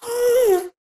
moan3.ogg